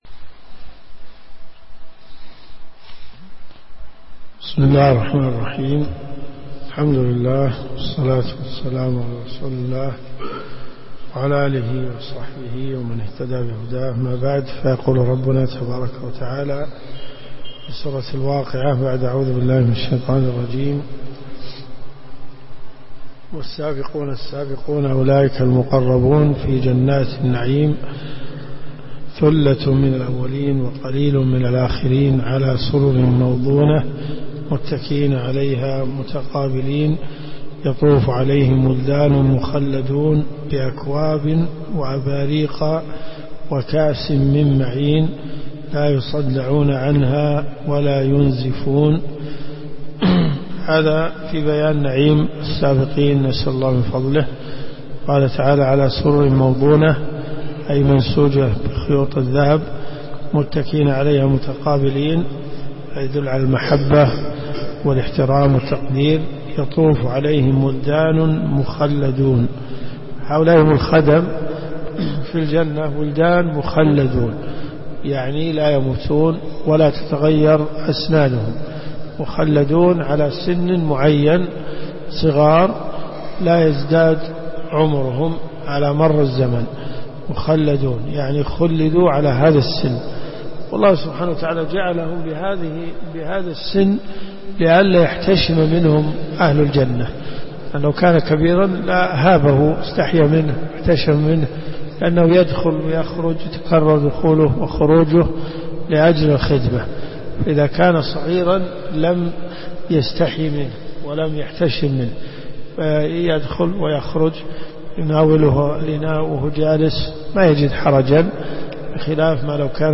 تفسير القران الكريم